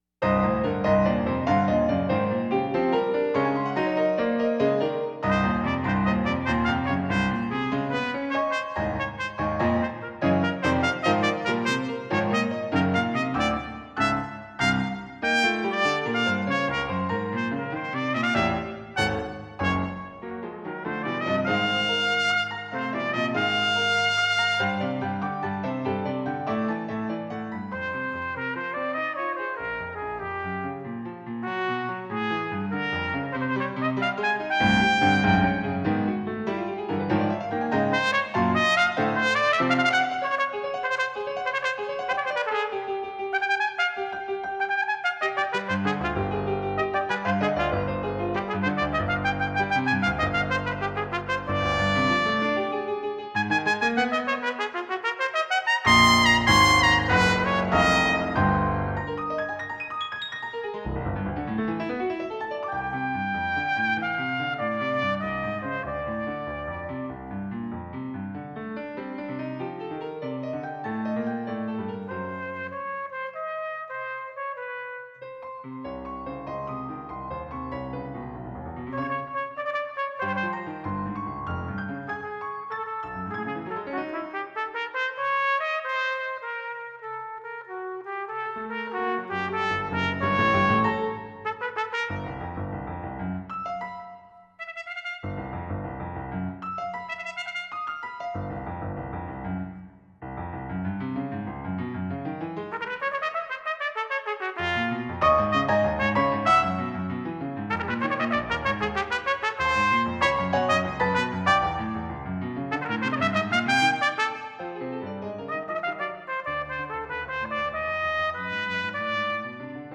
GP.3-Tpt-2-tpt-piano.mp3